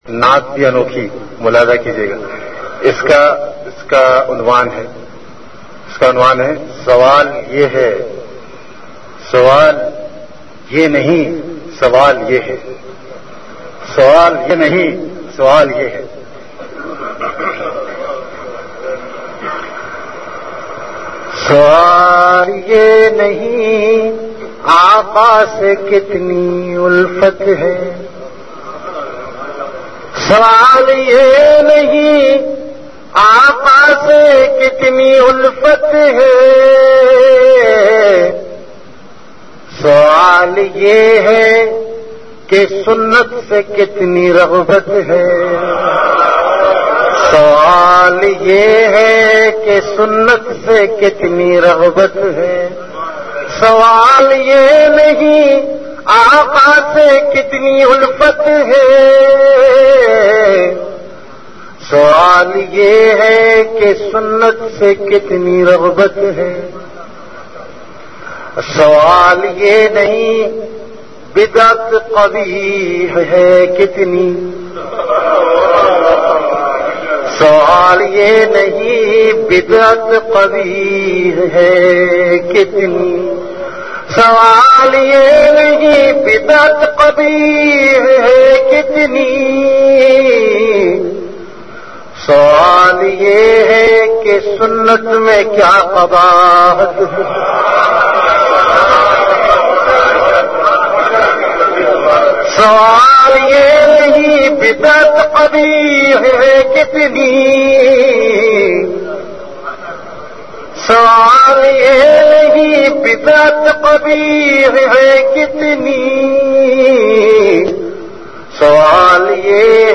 CategoryAshaar
Event / TimeAfter Isha Prayer
Sawal Ye Nahi Sawal Ye Hai (Naat).mp3